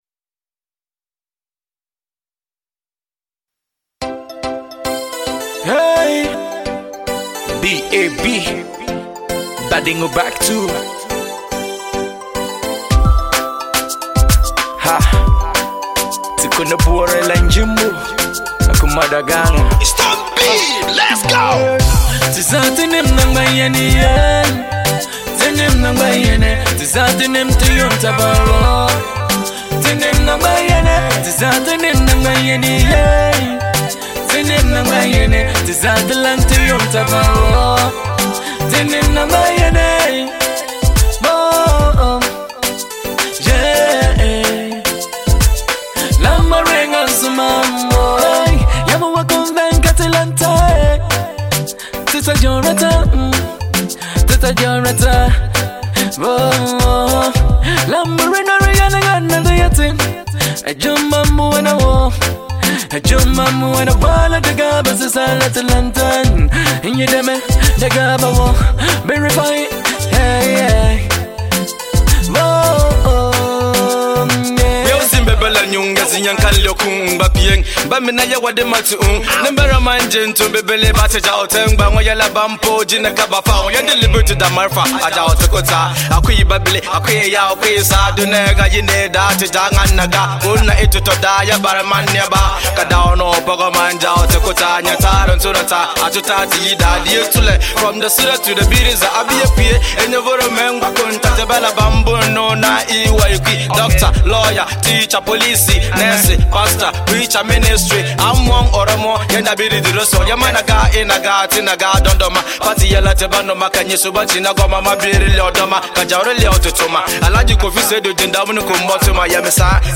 highlife